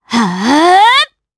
Pansirone-Vox_Casting3_jp.wav